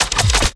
SCIMisc_Arm Weapon Heavy_01.wav